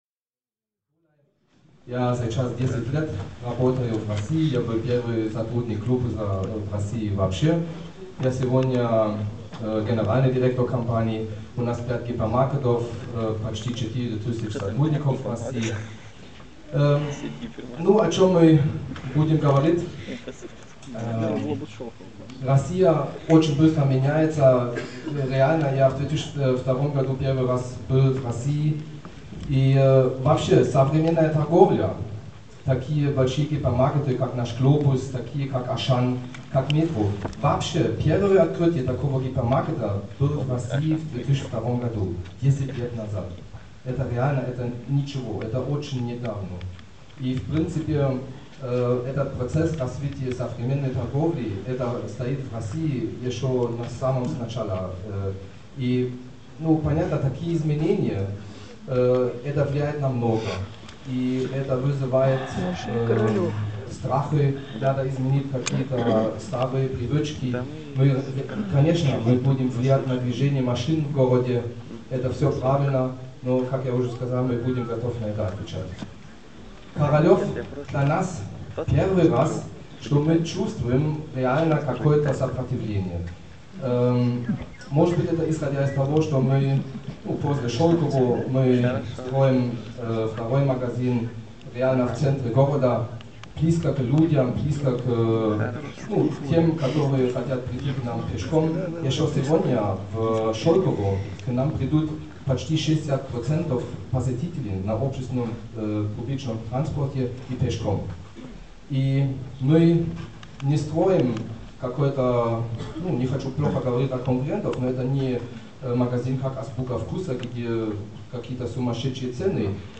Слушания по Глобусу